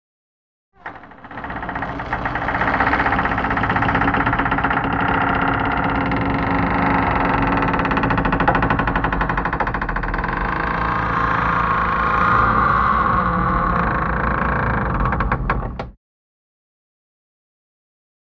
Sailing Ship; Creaking; Wooden Creaks And Groans.